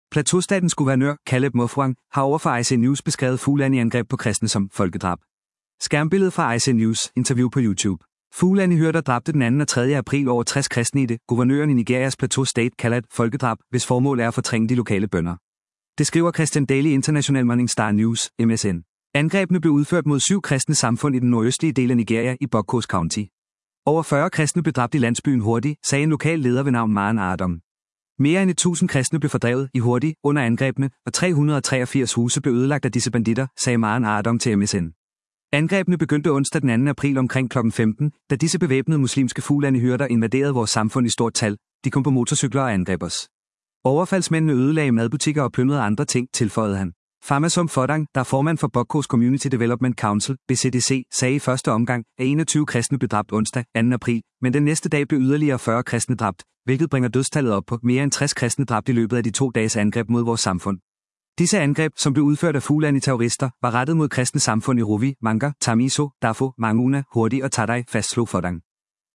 Plateau-statens guvernør, Caleb Mutfwang, har over for Arise News beskrevet Fulani-angreb på kristne som ”folkedrab”. Skærmbillede fra Arise News’ interview på YouTube.